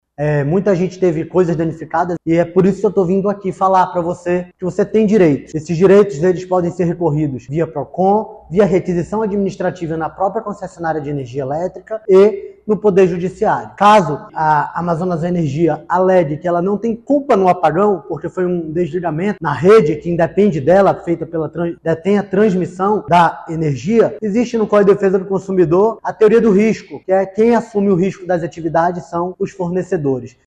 No documento, entregue na sede da concessionária, foram solicitadas diversas informações entre as quais, o total de consumidores afetados; esclarecimentos sobre o que provocou o desligamento do serviço; as medidas que foram tomadas para minimizar os impactos sofridos pela população e a reparação de possíveis perdas, ocasionadas pela interrupção do fornecimento de energia elétrica, como explica o diretor-presidente do órgão, Jalil Fraxe.
SONORA01_JALIL-FRAXE.mp3